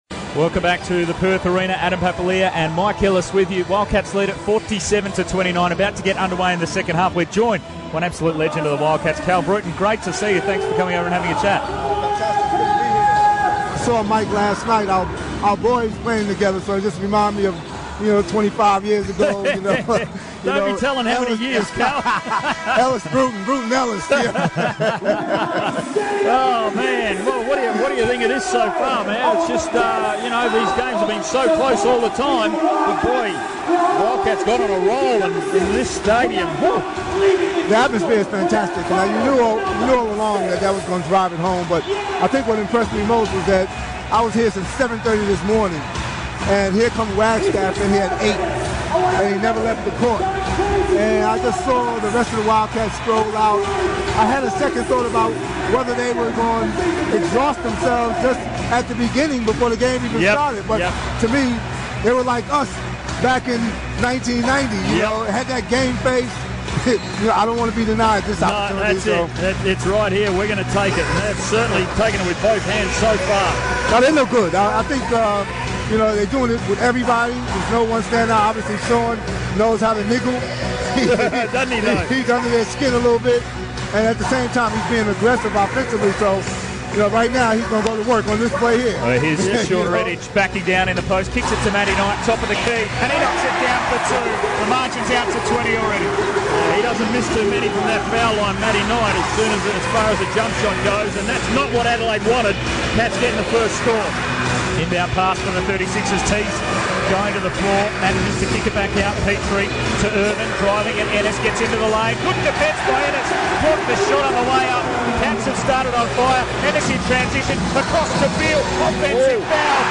live broadcast of the Perth Wildcats 2014 Championship win!